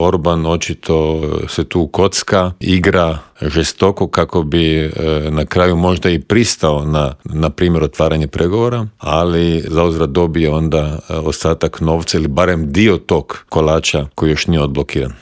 ZAGREB - Dok napetosti oko širenja Europske unije i nastavka pomoći Ukrajini traju, mađarski premijer Viktor Orban riskira i pokušava svojoj državi priskrbiti sredstva koja je Europska unija zamrznula, pojašnjava u Intervjuu Media servisa bivši inoministar Miro Kovač.